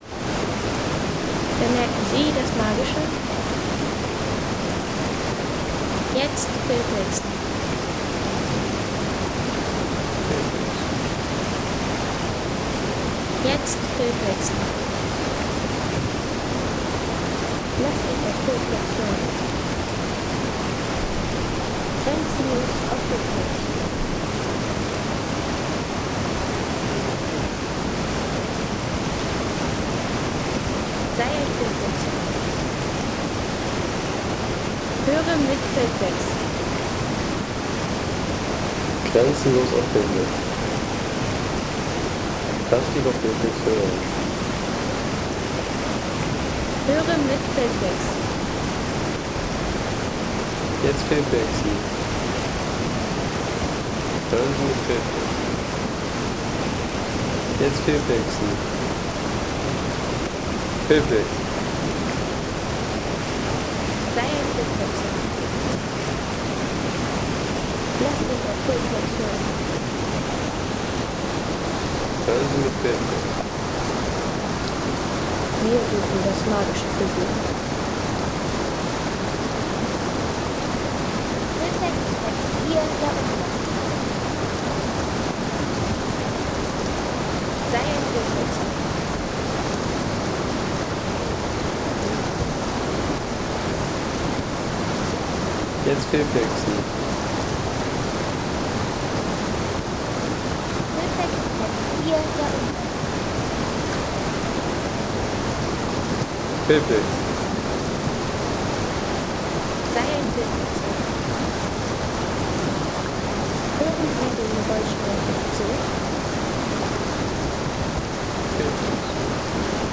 Isel – Umbaltal Home Sounds Landschaft Flüsse Isel – Umbaltal Seien Sie der Erste, der dieses Produkt bewertet Artikelnummer: 6 Kategorien: Landschaft - Flüsse Isel – Umbaltal Lade Sound....